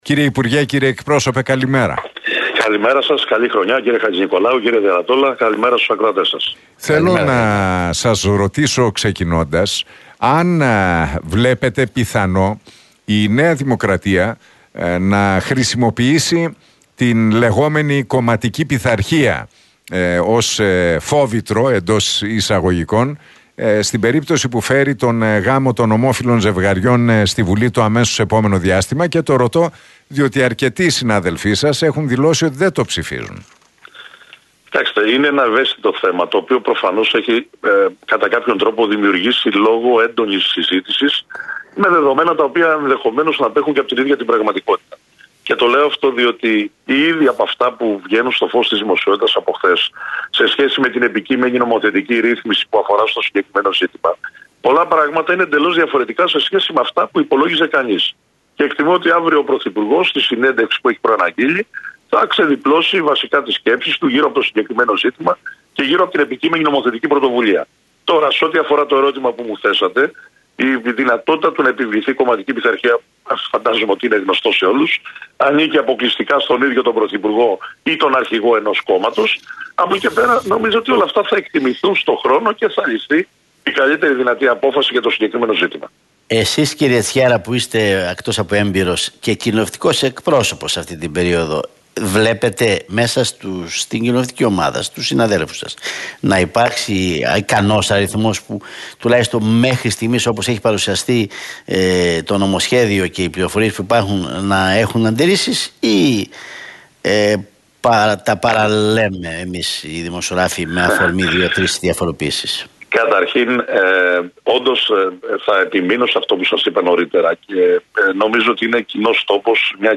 σε συνέντευξή του στον Realfm 97,8